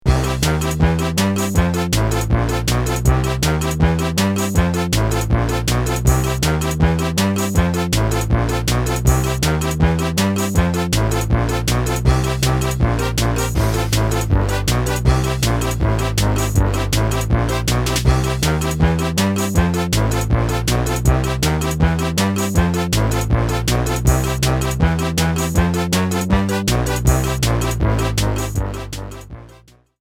Fade-out added
This is a sample from a copyrighted musical recording.